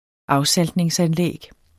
Udtale [ ˈɑwˌsalˀdneŋs- ]